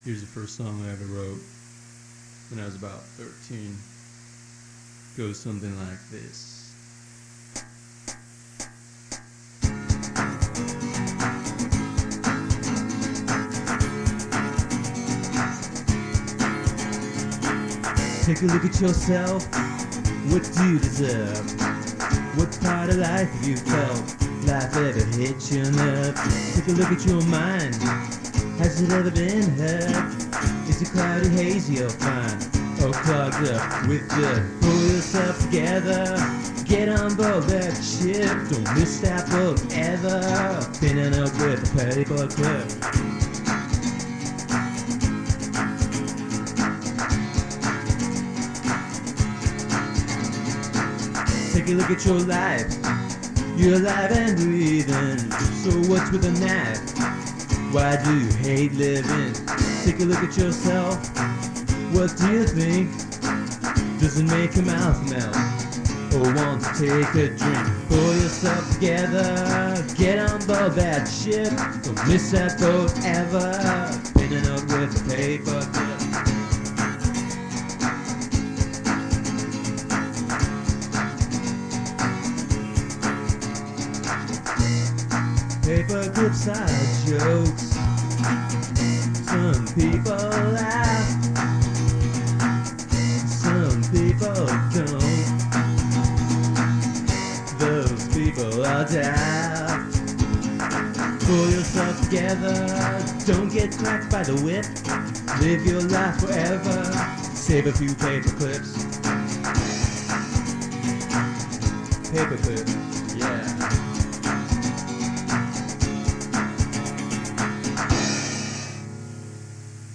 I simplified things (took out the riff and evened the odd bar phrases).  And with acoustic guitar and drum machine, hit record.